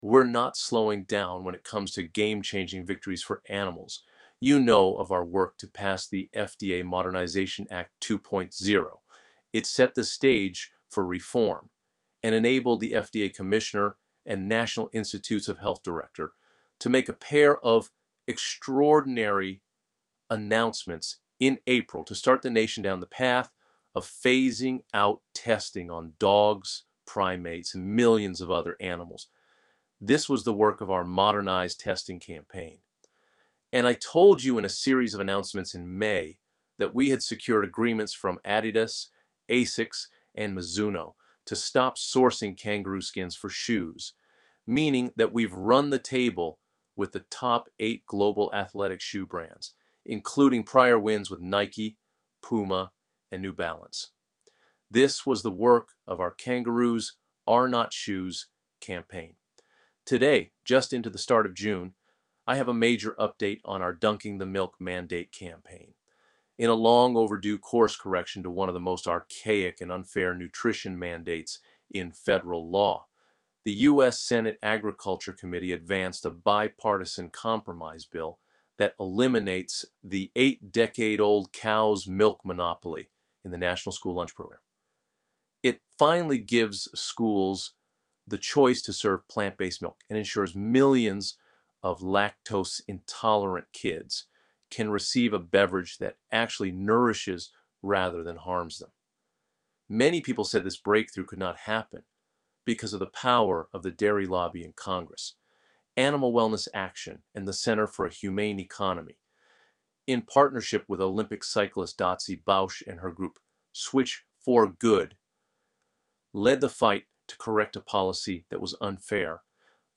You can listen to an AI-generated reading of this story here: